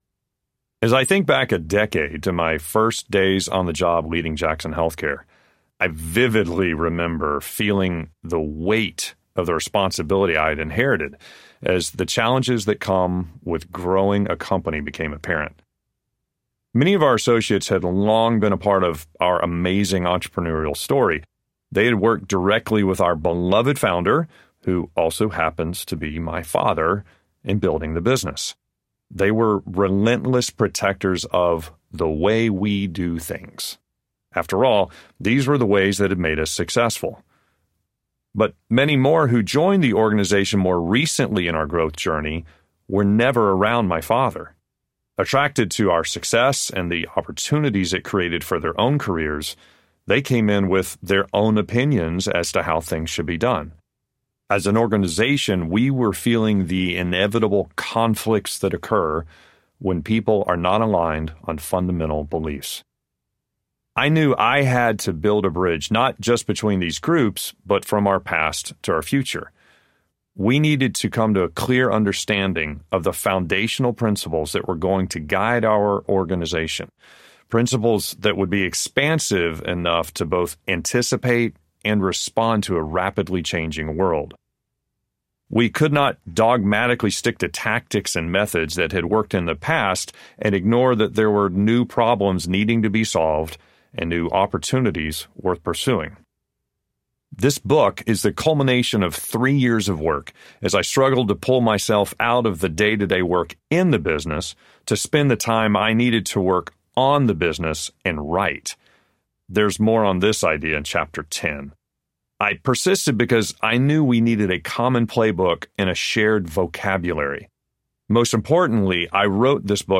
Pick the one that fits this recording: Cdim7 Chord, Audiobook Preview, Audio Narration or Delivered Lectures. Audiobook Preview